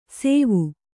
♪ sēvu